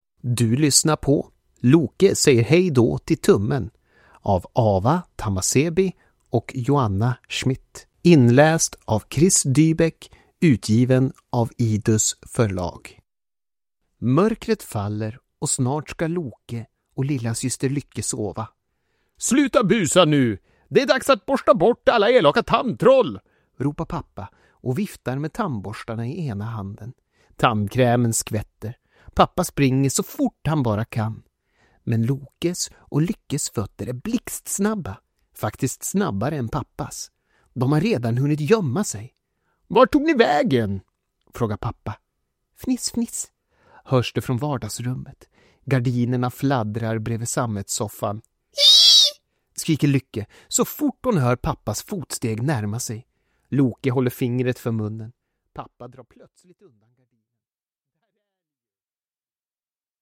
Loke säger hej då till tummen – Ljudbok